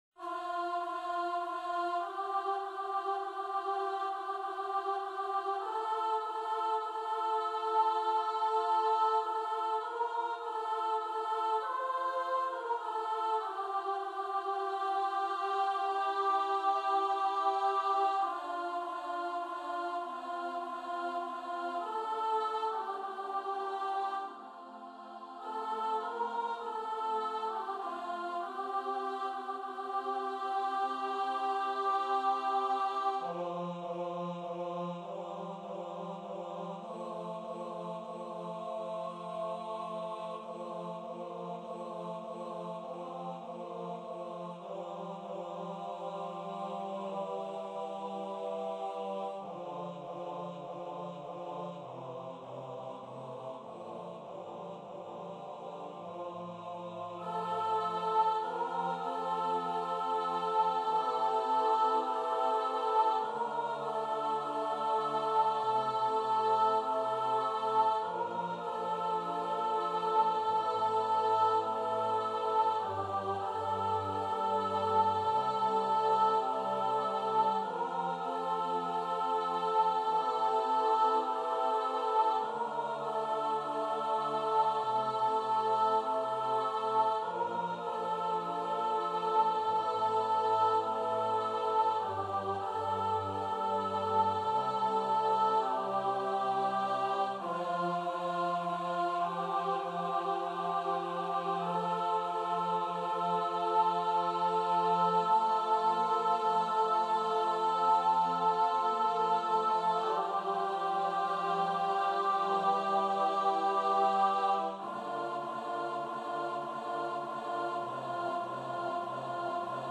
- Œuvre pour choeur à 8 voix mixtes (SSAATTBB) a capella
MP3 rendu voix synth.
Soprano 1